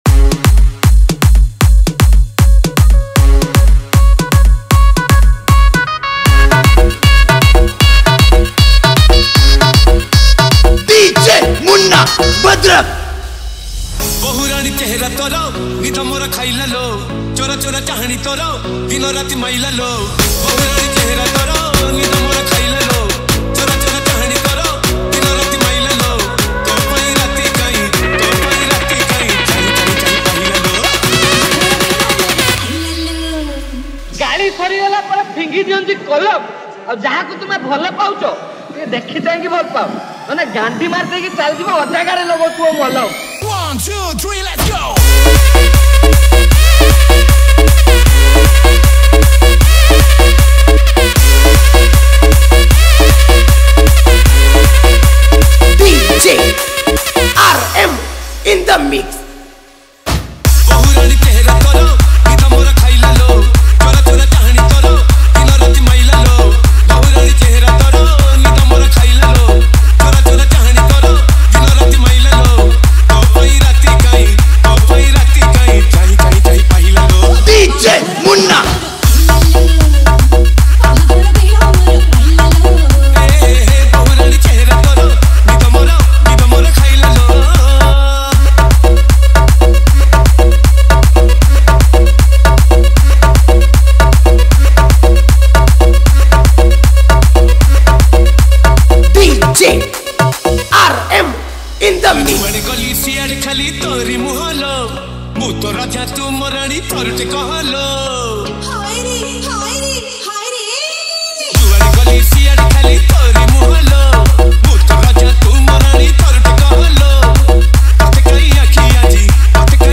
• Category: ODIA SINGLE REMIX